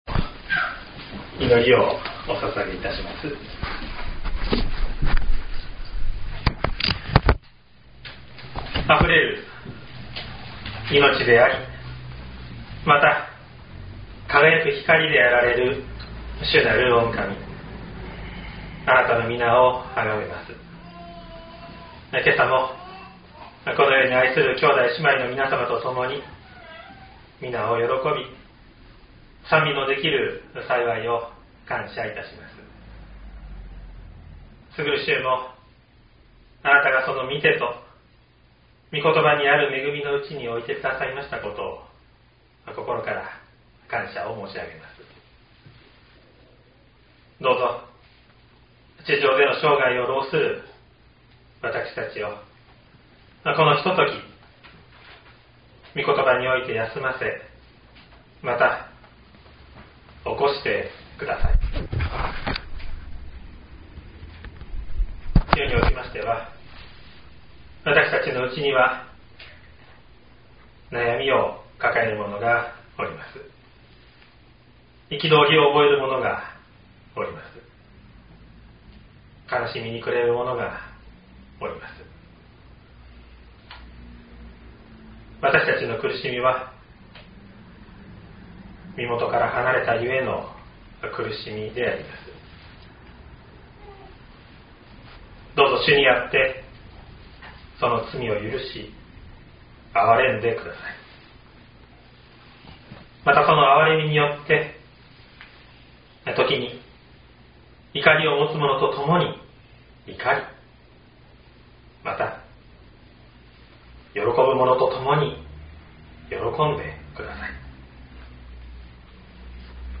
2022年05月08日朝の礼拝「招く神へと歩む道」西谷教会
音声ファイル 礼拝説教を録音した音声ファイルを公開しています。